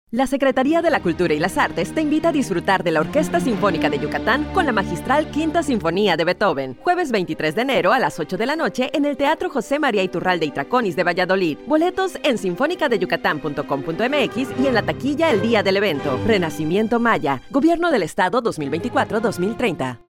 Spot de radio
Spot-de-radio-P1-Valladolid.mp3